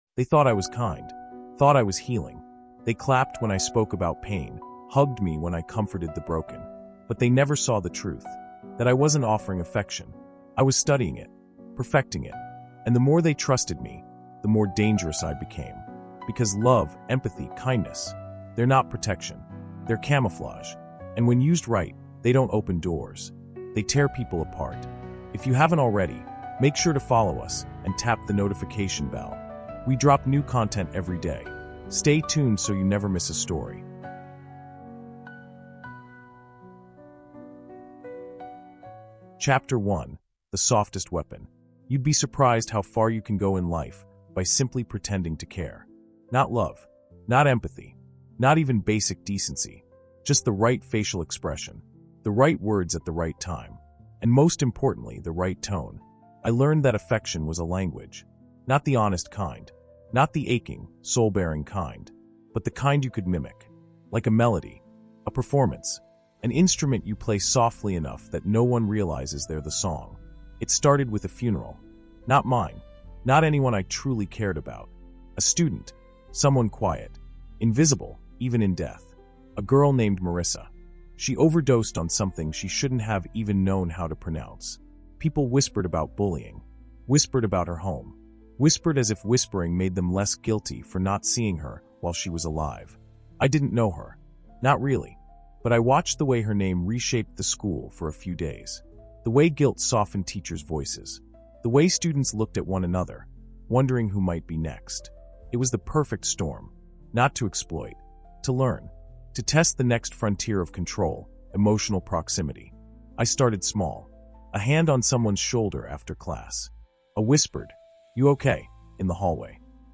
Inside the Mind of a Master Manipulator | Weapons of Affection | Audiobook